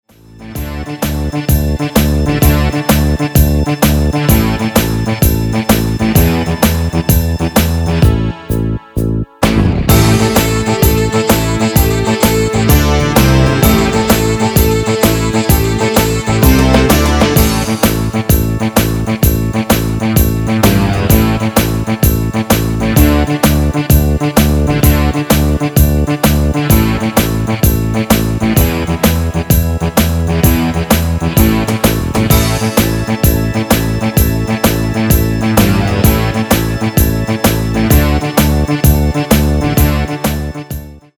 Extended MIDI File Euro 12.00
Demo's zijn eigen opnames van onze digitale arrangementen.